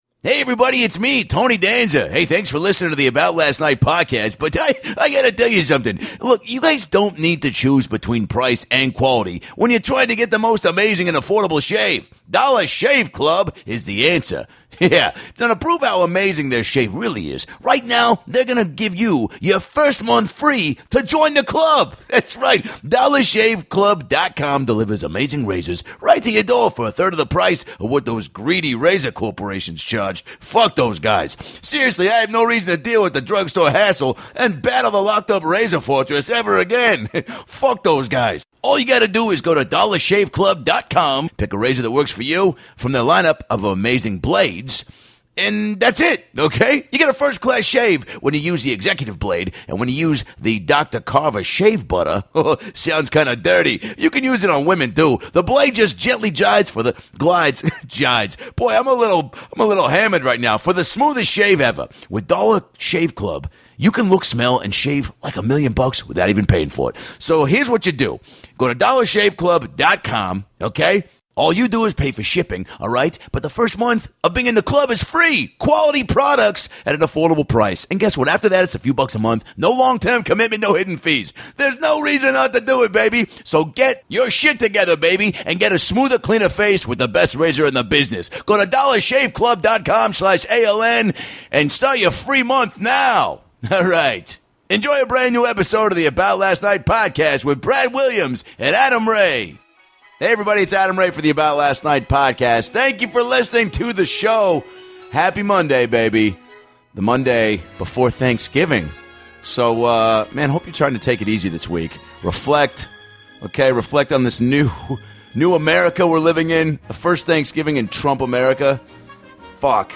From his thoughts on President Trump, to what his doll catchphrase would be, writing his new hour special, and rapping for LL Cool J on LIP SYNC BATTLE, it's another classic wild episode with lots of laughs and tangents that you'll be glad to go on.